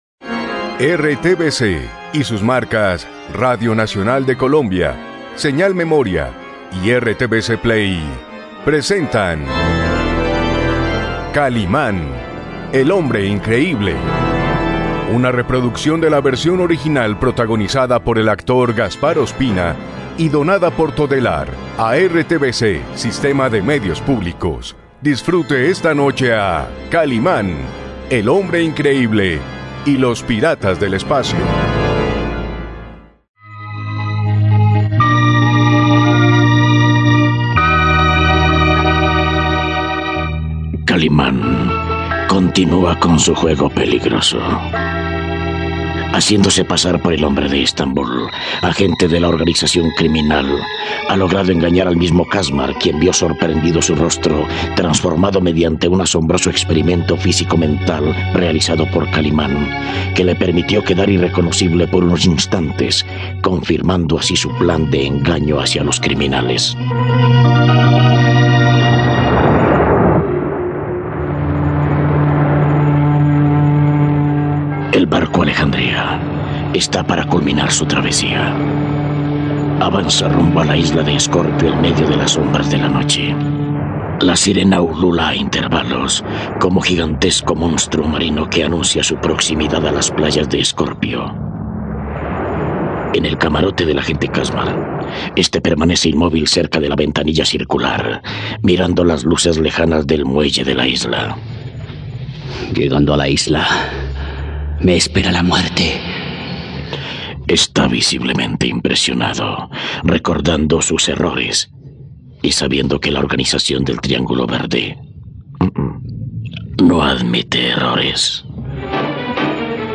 Radionovela.